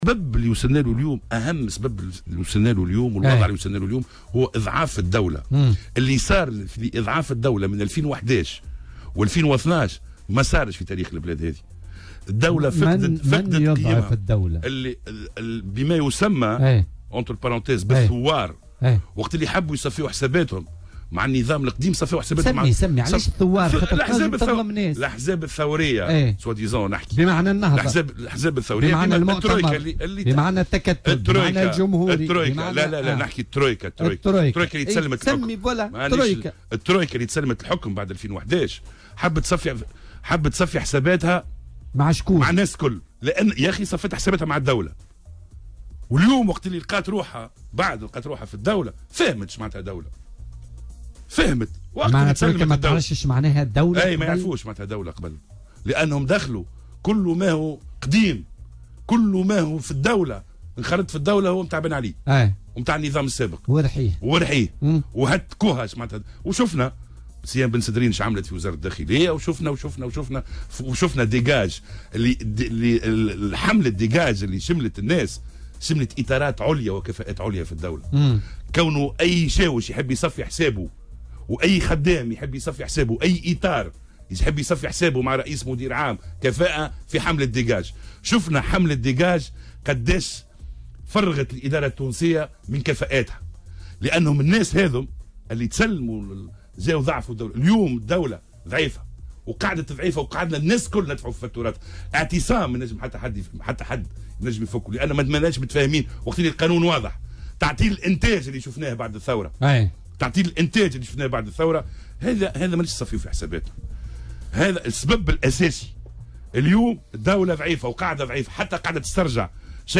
وأضاف في مداخلة له اليوم في برنامج "بوليتيكا" أن "الترويكا" أو "الأحزاب الثورية" أرادت تصفية حساباتها مع النظام السابق فكانت النتيجة إضعاف الدولة، وفق تعبيره.